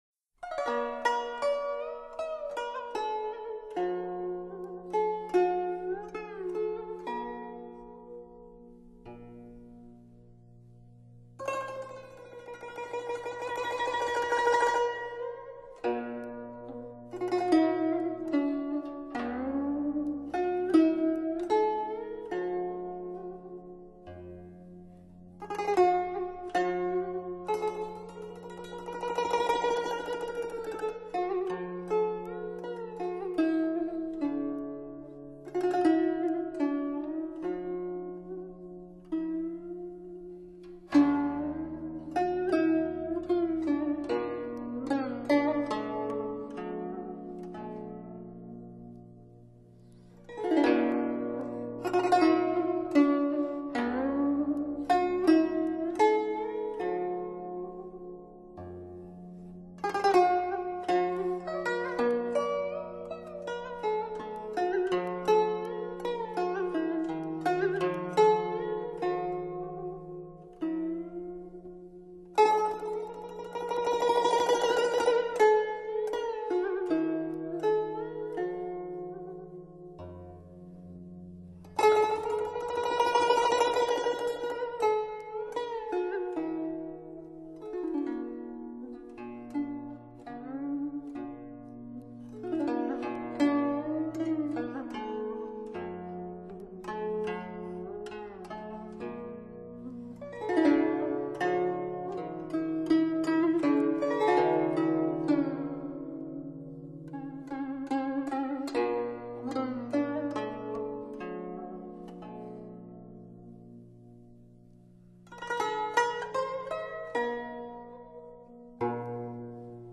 古筝 湘妃泪